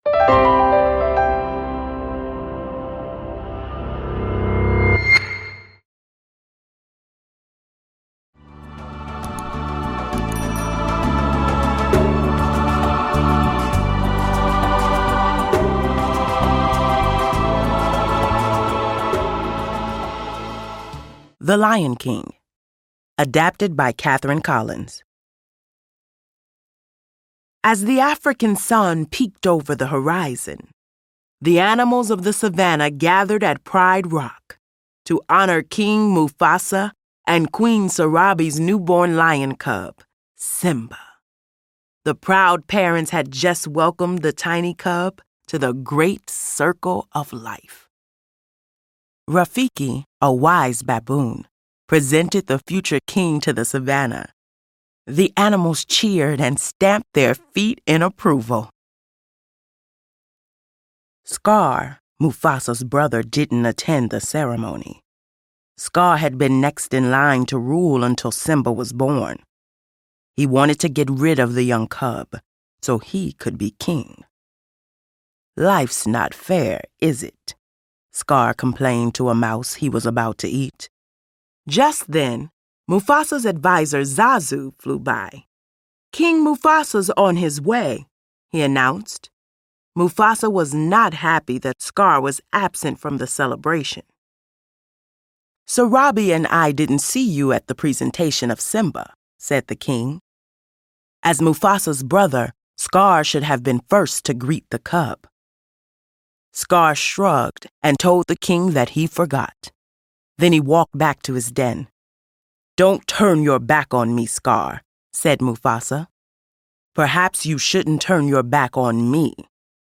The Yellow Face: Sherlock Holmes’ Most Puzzling Mystery (Audiobook)